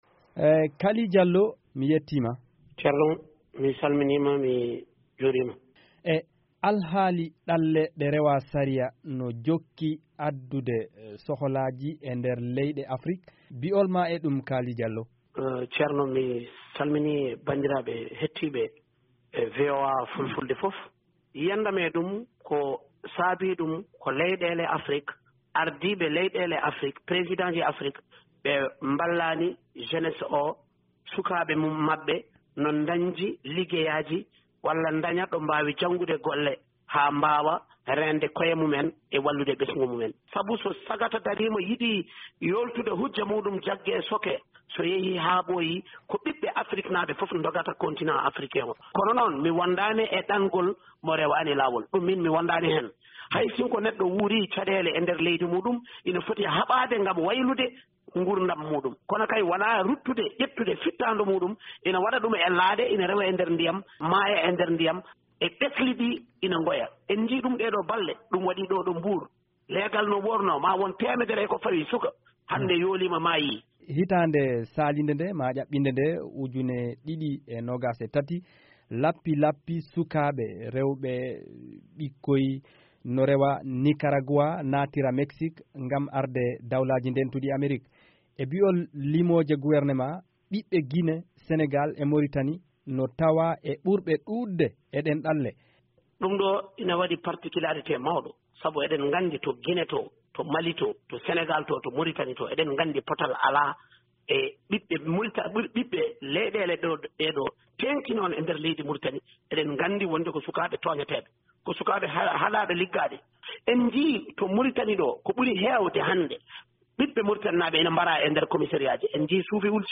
Daande Fulbe yewtidii e Kaali Jallo, sarɗiyankeejo Moritaninaajo, ko faatii e alhaali ɗalle ɗe ɗoftaaki sariya.